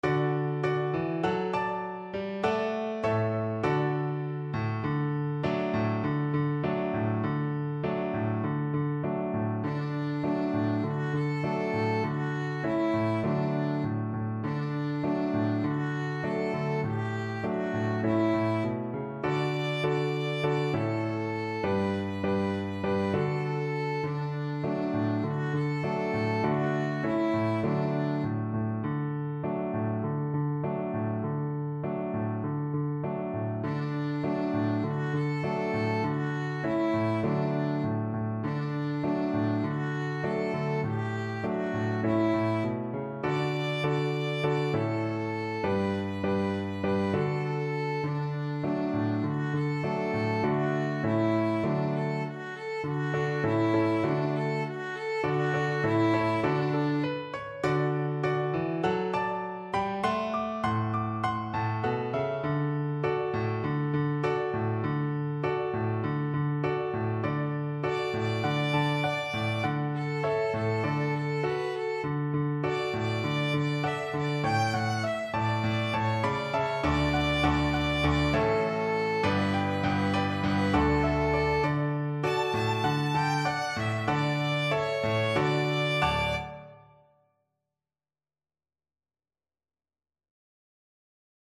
World Africa Nigeria L'abe igi orombo
Violin
D major (Sounding Pitch) (View more D major Music for Violin )
Joyfully =c.100
4/4 (View more 4/4 Music)
Traditional (View more Traditional Violin Music)
labe_igi_orombo_VLN.mp3